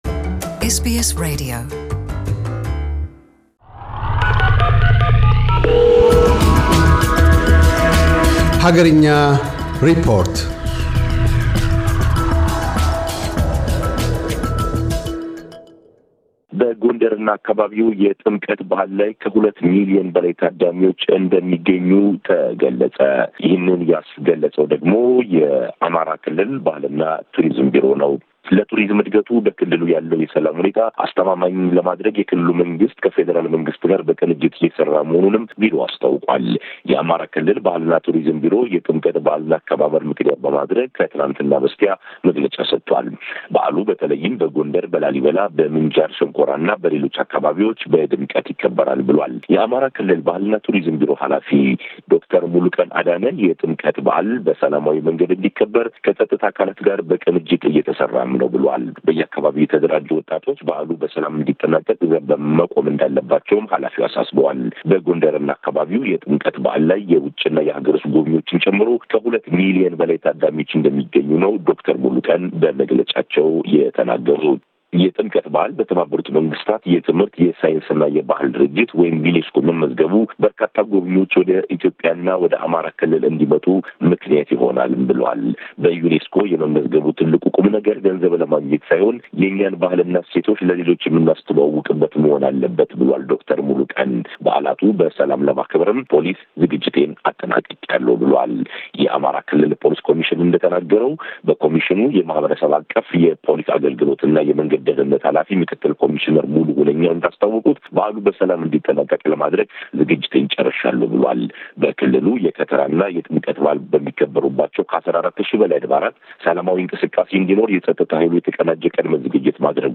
አገርኛ ሪፖርት - በጎንደርና አካባቢዋ ከሁለት ሚሊየን በላይ ታዳሚዎች ለበዓለ ጥምቀት እንደሚገኙ በአማራ ክልል ባሕልና ቱሪዝም ቢሮ መነገሩን ቀዳሚ ትኩረቱ አድርጓል።